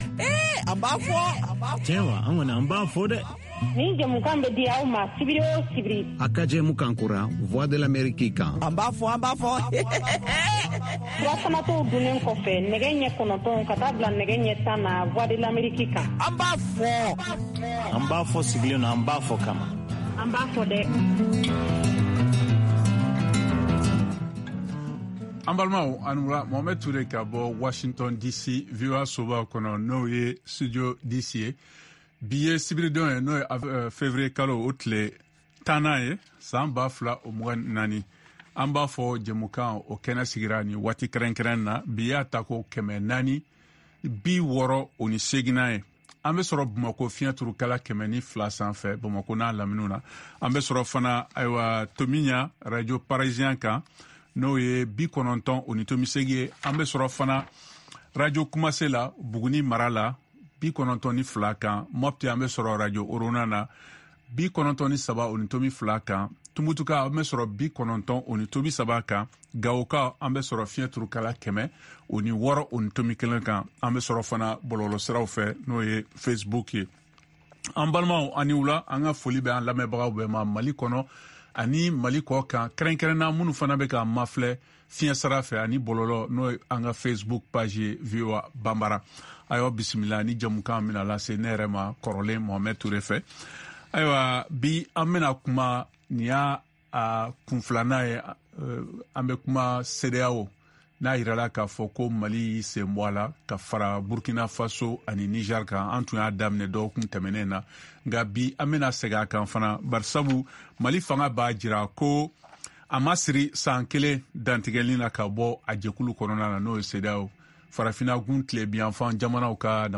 An ba fɔ ye Voix de l’Ameriki ka hakili n’falen n’falen kƐnƐ ye lamƐli kƐlaw ani jamana ɲƐmɔkɔw jamana kunkan kow kan.